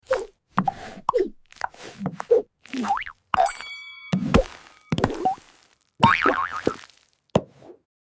Chocolate vs Marshmallow — Soft